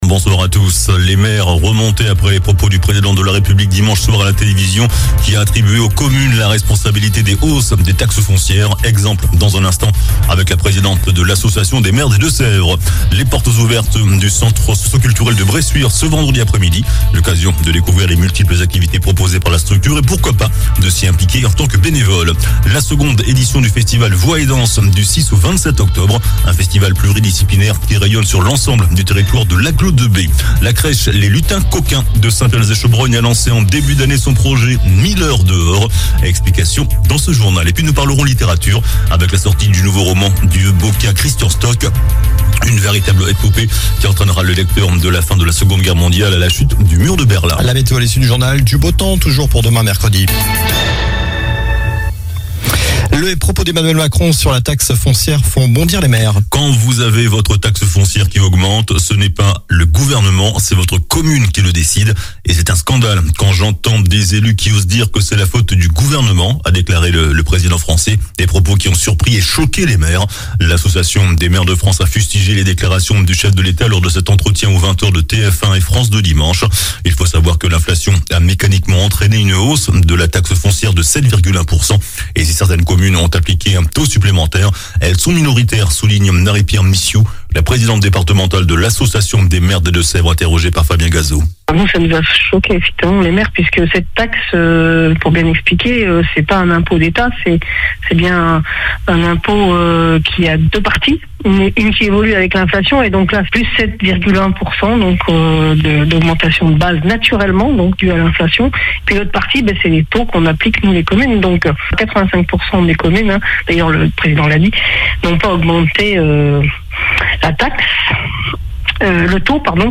JOURNAL DU MARDI 26 SEPTEMBRE ( SOIR )